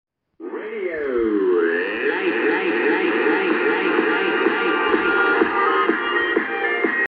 Ich habe hier grade einen Freifunksender (Ein schönerer Name für "Piratensender") aus den Niederlanden empfangen! Von S1 bis S7 war alles dabei. Aber durchweg einen SINPO von einer guten 4 bis 5!